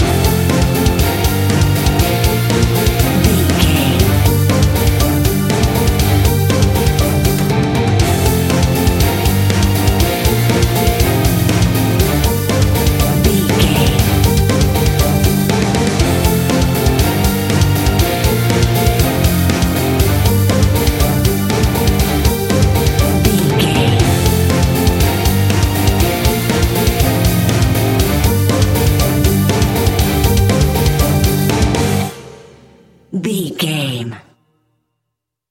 Epic / Action
Fast paced
Aeolian/Minor
guitars
scary rock
Heavy Metal Guitars
Metal Drums
Heavy Bass Guitars